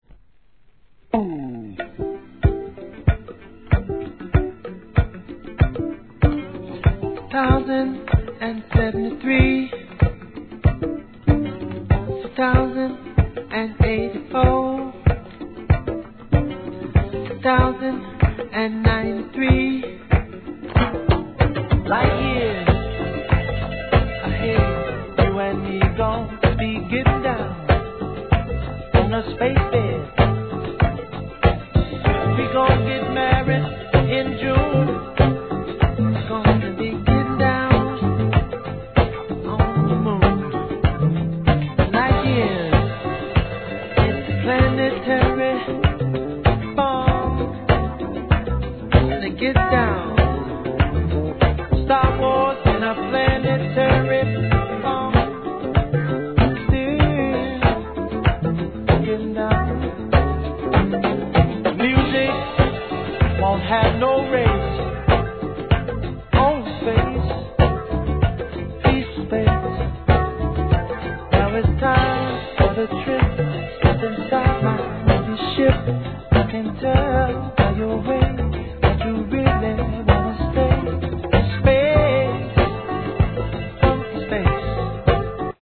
¥ 770 税込 関連カテゴリ SOUL/FUNK/etc...
スペイシーな心地よいトラックに優しく歌い上げるヴォーカルに酔いしれます♪再発 No. タイトル アーティスト 試聴 1.